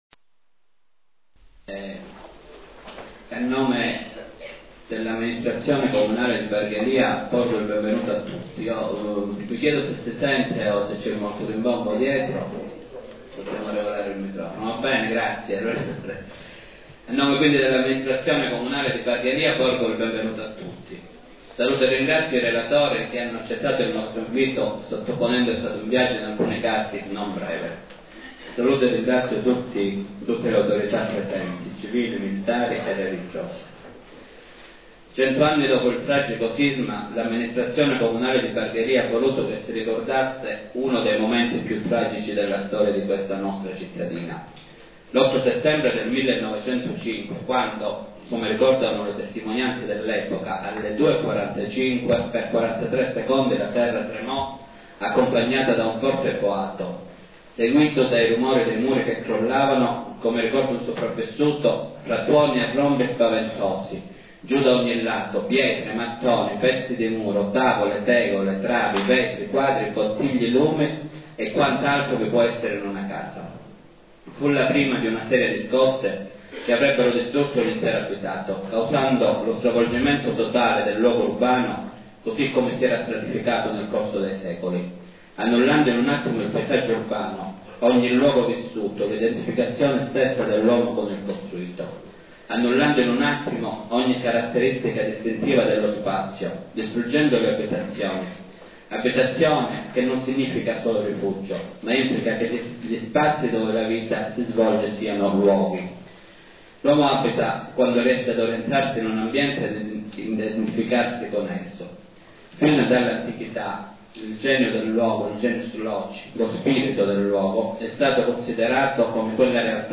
convegno sindaco.mp3